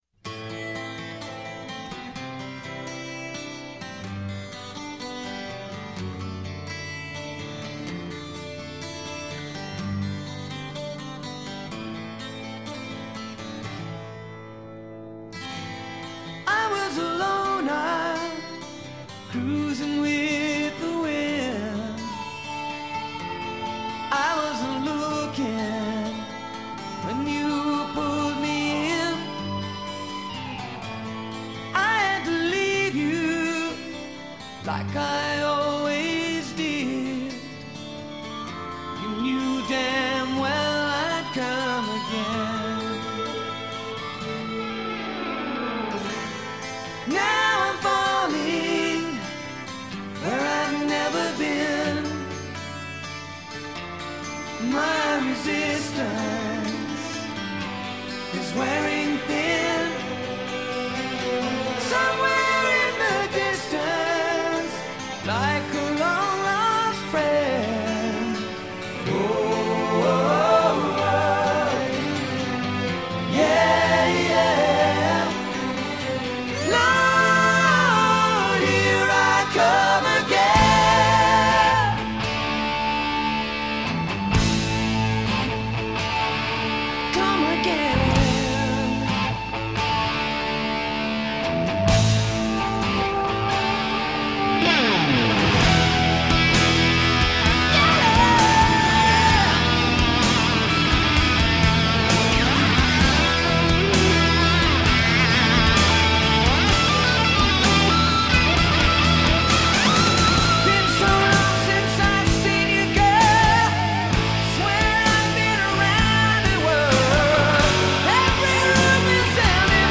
MusicRock